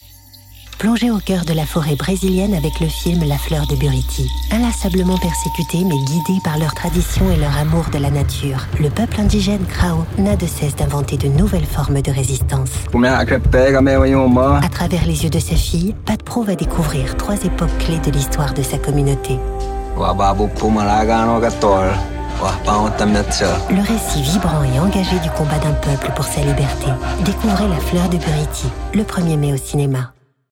Movie Trailers
French voice over actress native from France, neutral accent.
My voice can be natural, sensual, dramatic, playful, friendly, institutional, warm and much more …
Mezzo-Soprano